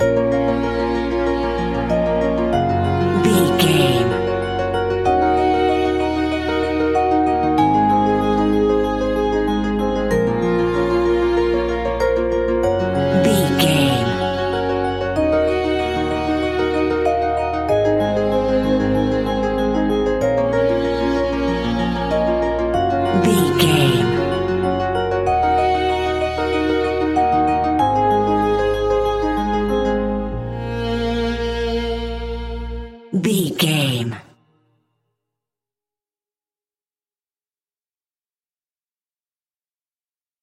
Music
Aeolian/Minor
B♭
dreamy
ethereal
melancholy
hopeful
piano
violin
cello
percussion
electronic
synths
synth drums